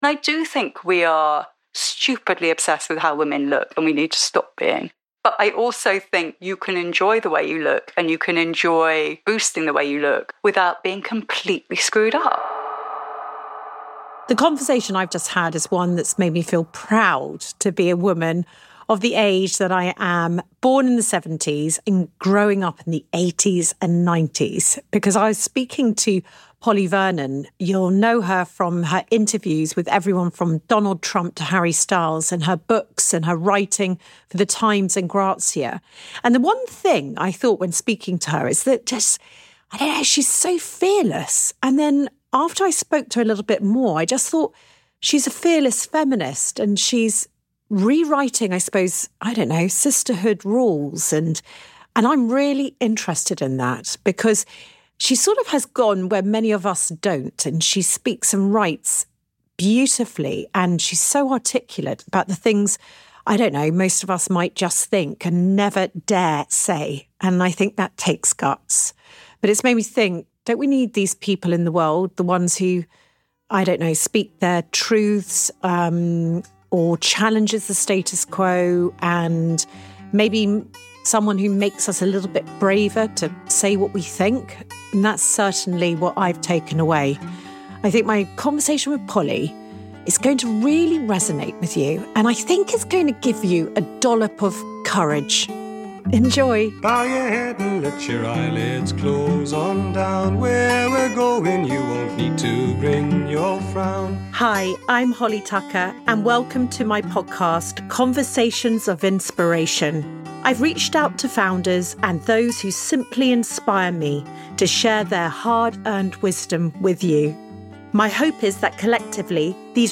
This is a conversation that challenges, comforts and might just make you a little braver too.